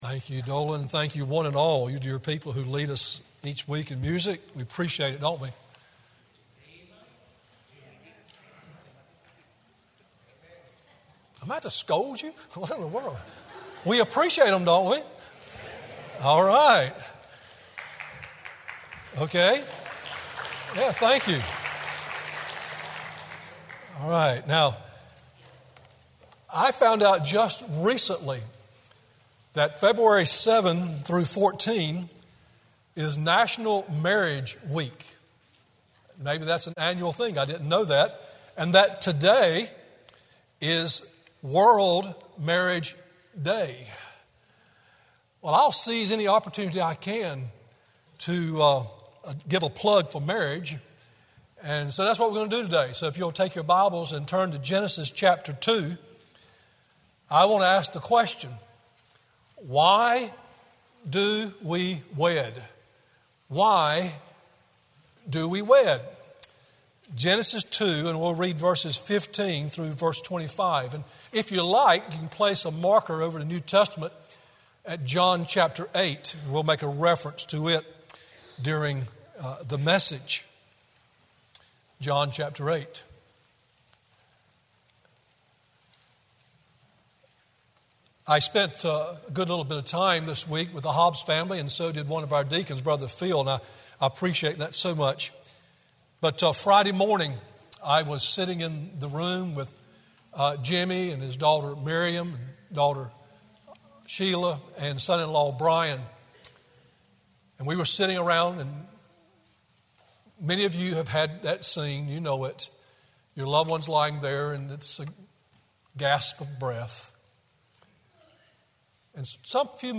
Sermon Audios/Videos - Tar Landing Baptist Church
Morning WorshipGenesis 2:15-25; Ephesians 5